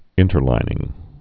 (ĭntər-līnĭng)